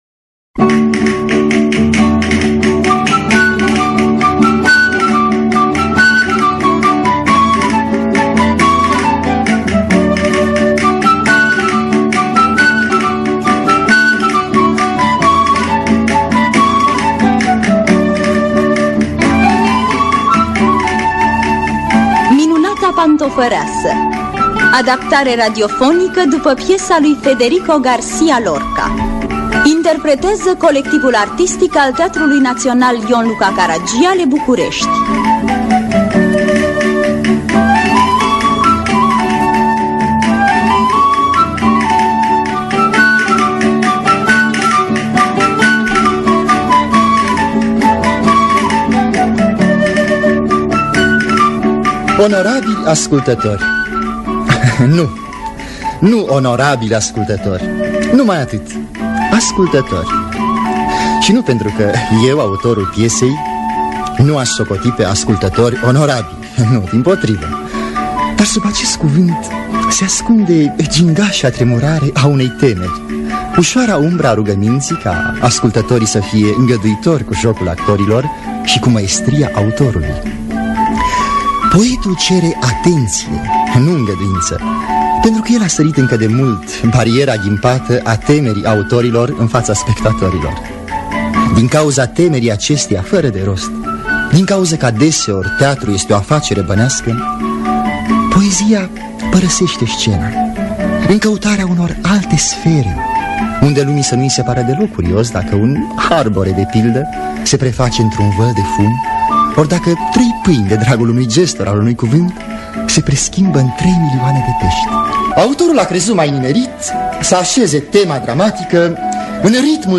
Minunata pantofăreasă de Federico García Lorca – Teatru Radiofonic Online